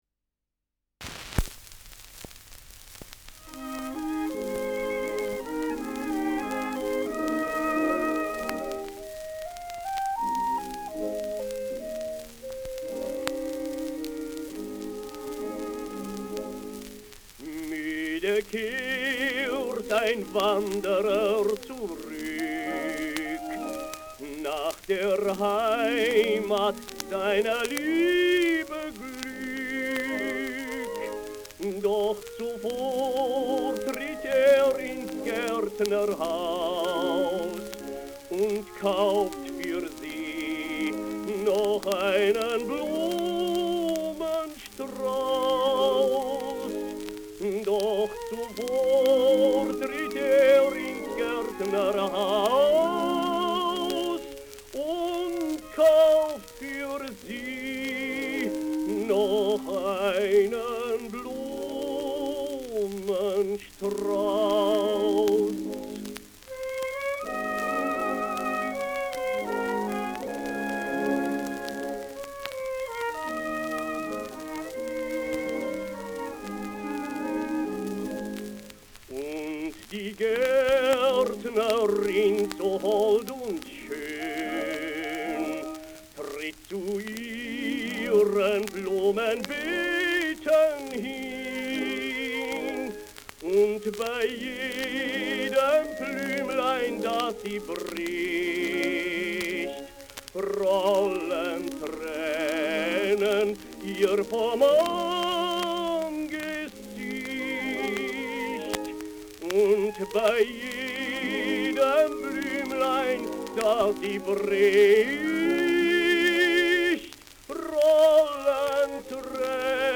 Schellackplatte